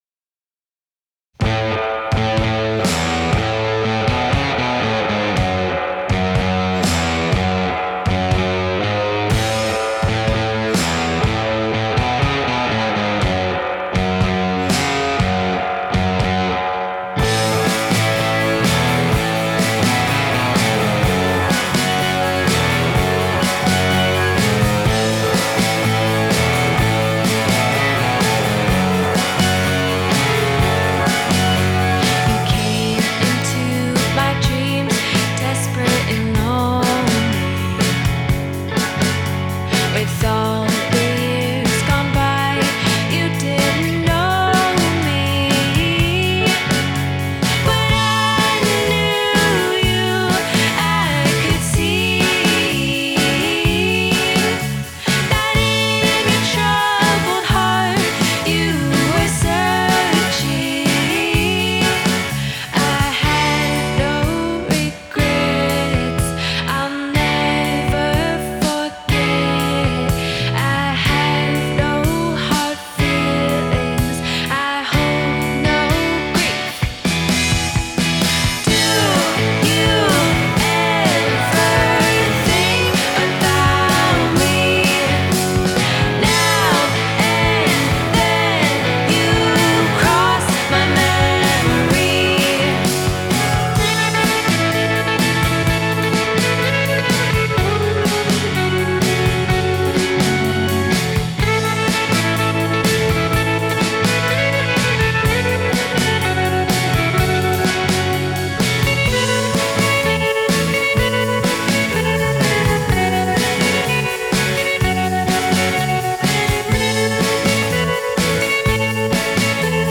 Genre: indie pop, dream pop, indie rock